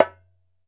ASHIKO 4 0JR.wav